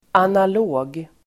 Uttal: [anal'å:g]